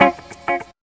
4605L GTRCHD.wav